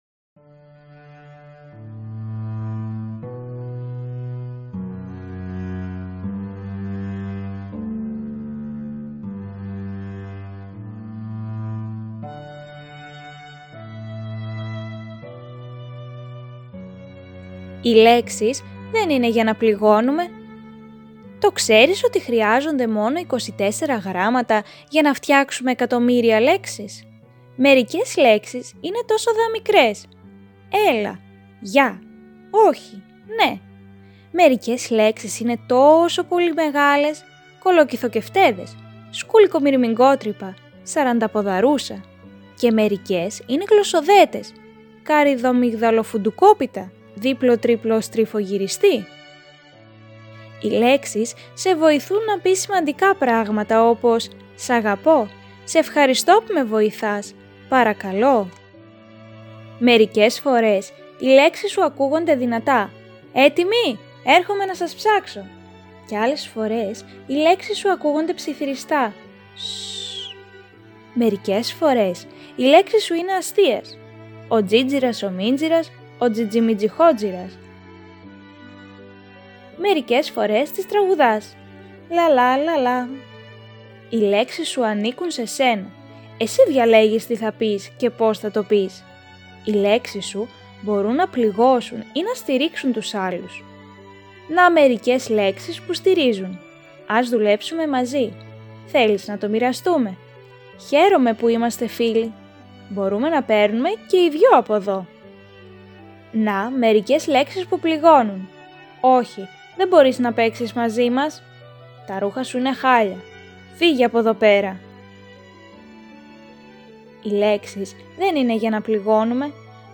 Βιβλιοθήκη Ψηφιακής Αφήγησης